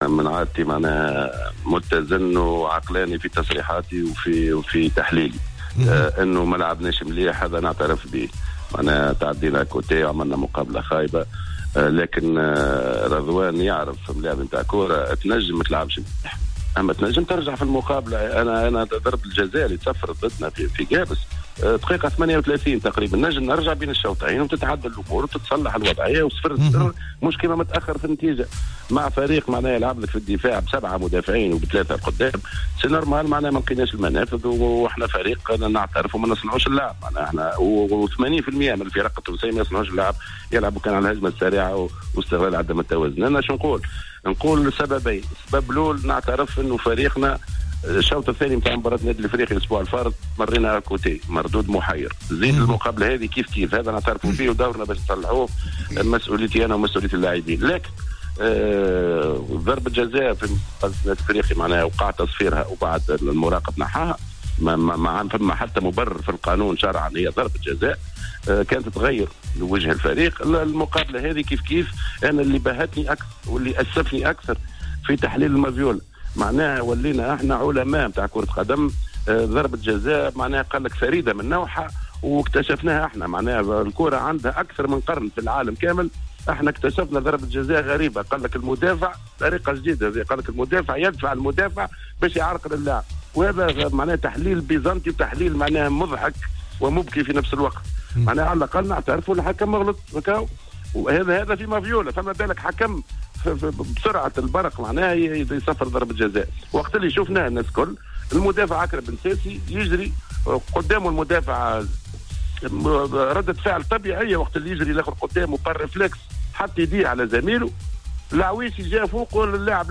مداخلة في حصة "Planète Sport"